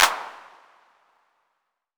• Hand Clap Sound C# Key 17.wav
Royality free clap sample - kick tuned to the C# note. Loudest frequency: 3274Hz
hand-clap-sound-c-sharp-key-17-erR.wav